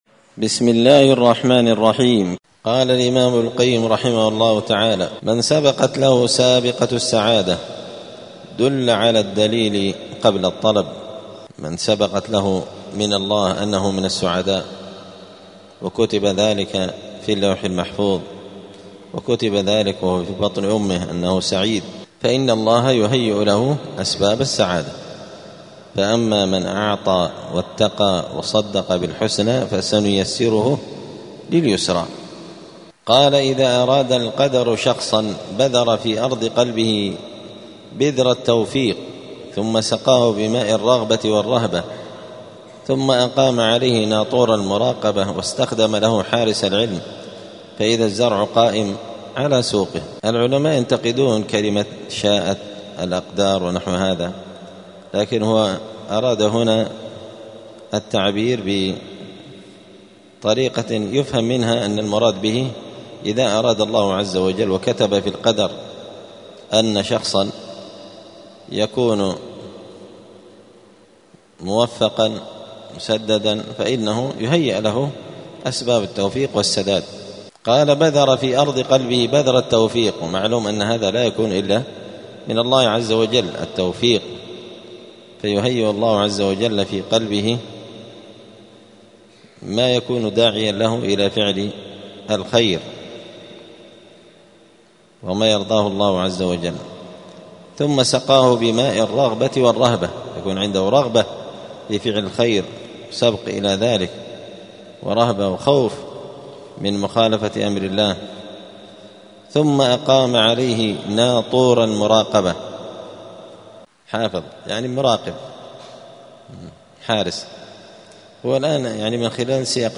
*الدرس الرابع والعشرون (24) {فصل: من سبقت له سابقة السعادة دل على الدليل قبل الطلب}*
دار الحديث السلفية بمسجد الفرقان قشن المهرة اليمن